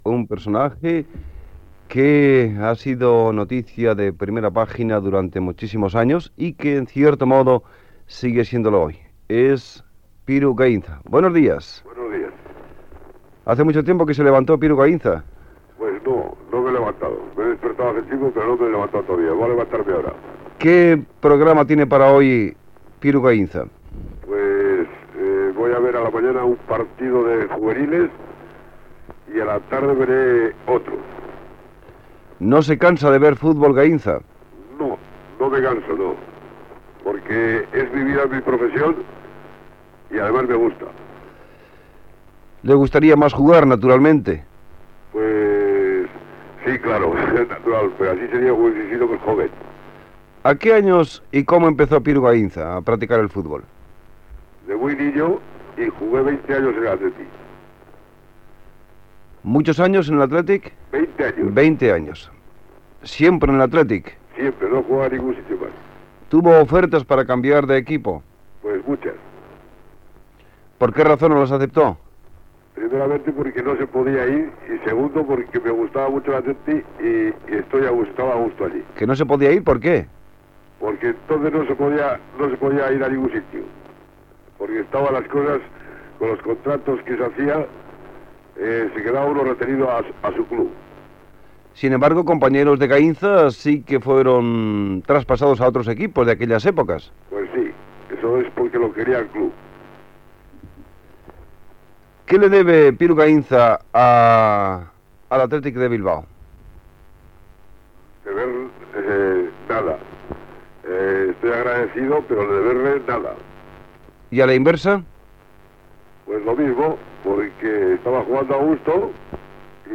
Entrevista al jugador i entrenador de futbol basc Piru Gaínza